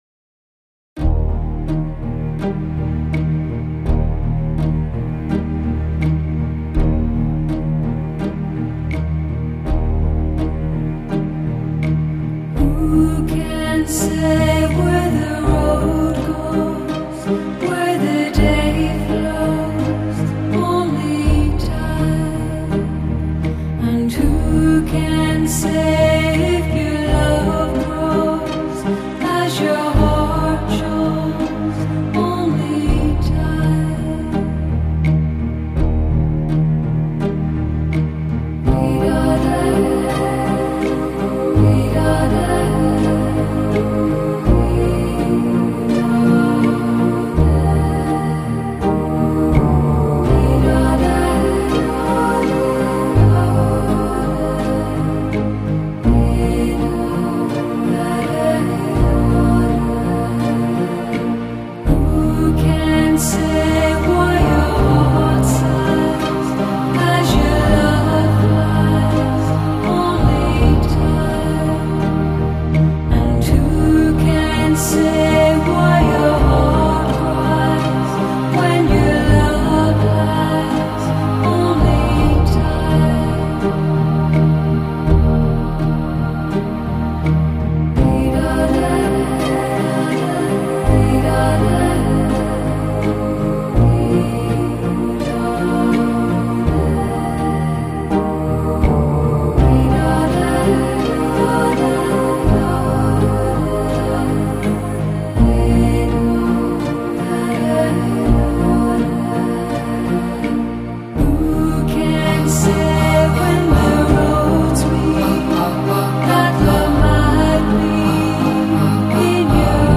扣人心弦的歌声，真正魅力的典藏。